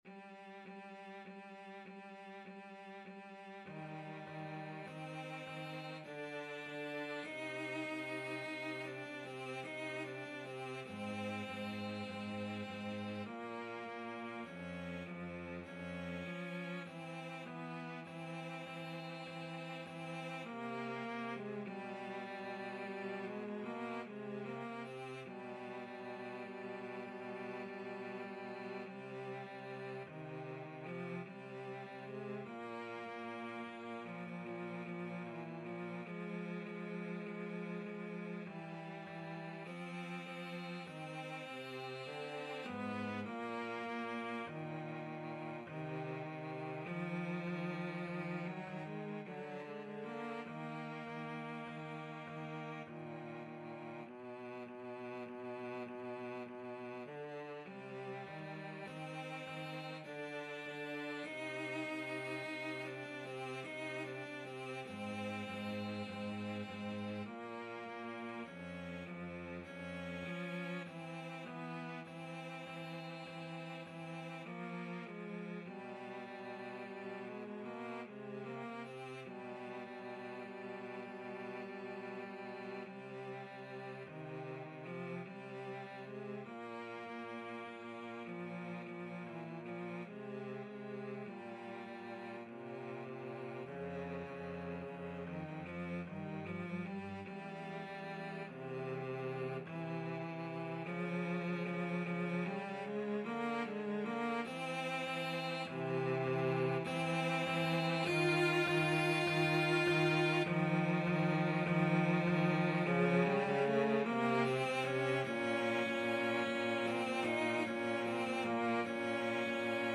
Free Sheet music for Cello Duet
Eb major (Sounding Pitch) (View more Eb major Music for Cello Duet )
Andantino = 50 (View more music marked Andantino)
3/4 (View more 3/4 Music)
Classical (View more Classical Cello Duet Music)